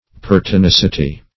Pertinacity \Per`ti*nac"i*ty\, n. [Cf. F. pertinacit['e].]